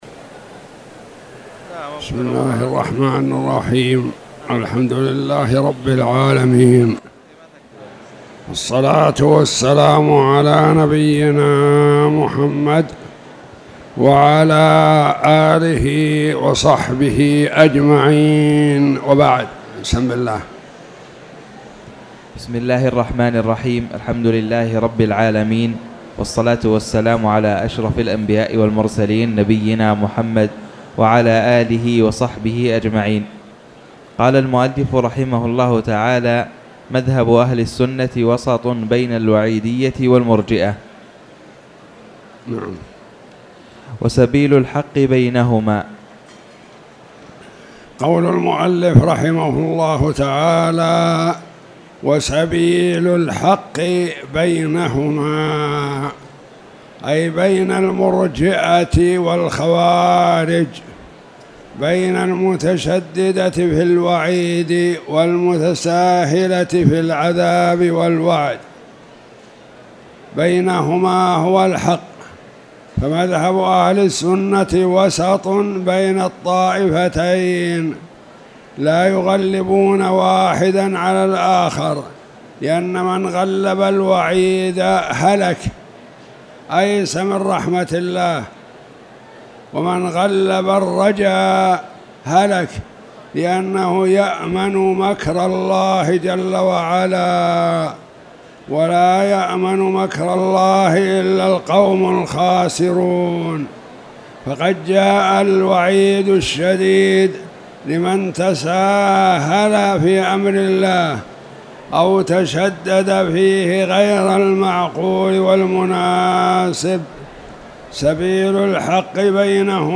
تاريخ النشر ٦ شوال ١٤٣٧ هـ المكان: المسجد الحرام الشيخ